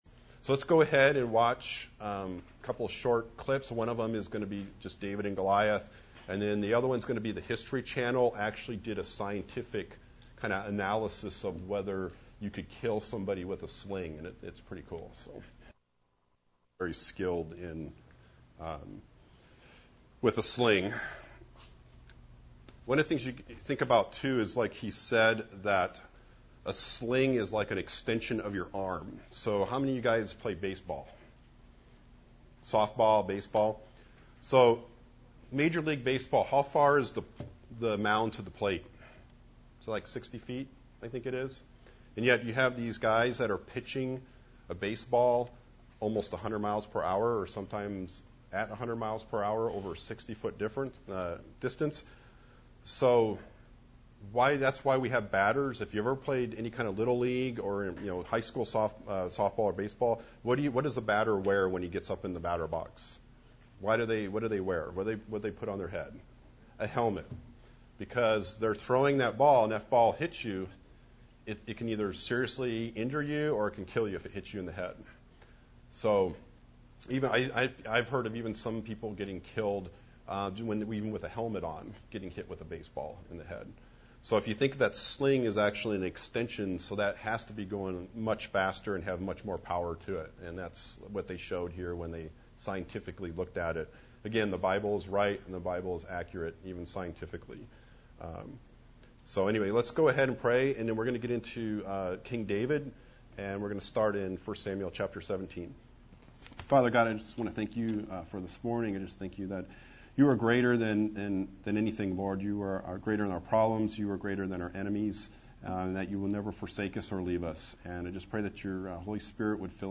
Service Type: Student Ministries